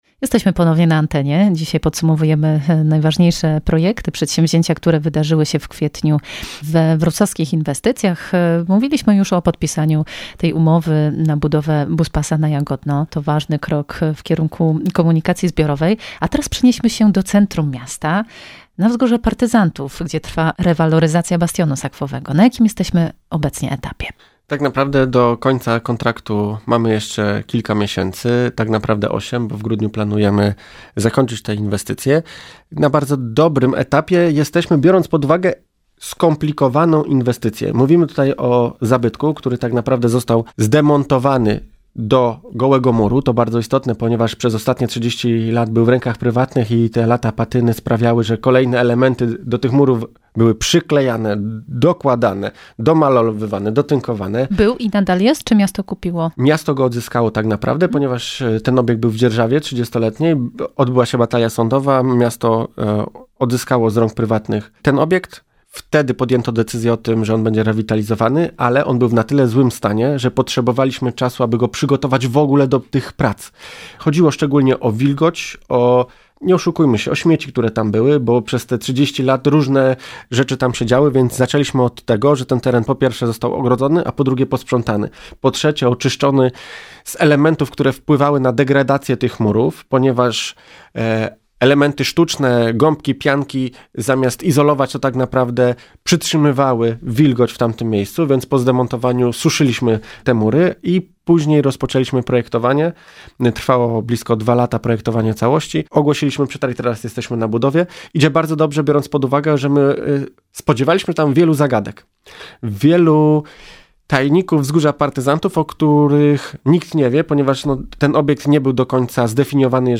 Podpisanie umowy o budowie buspasa na Jagodno, trwająca rewaloryzacja Bastionu Sakwowego i trasa rowerowa łącząca ul. Mokronoską z gminą Kąty Wrocławskie. W rozmowie